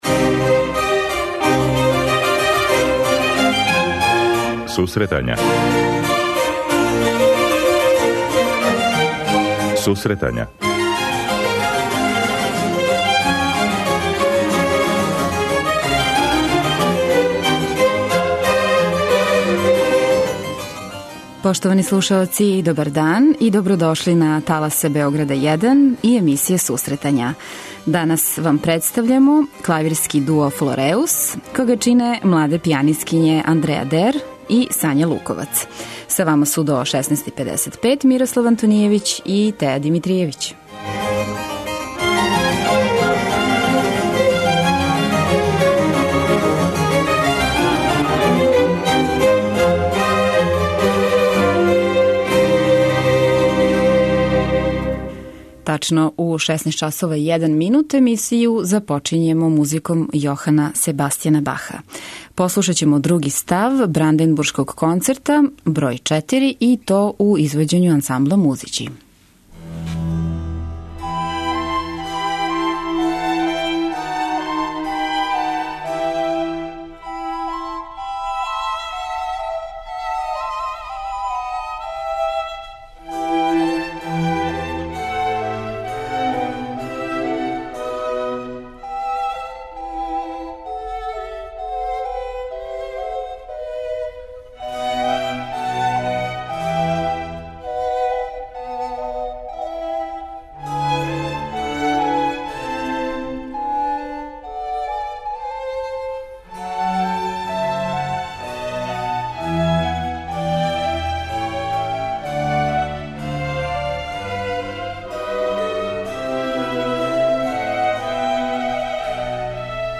преузми : 26.09 MB Сусретања Autor: Музичка редакција Емисија за оне који воле уметничку музику.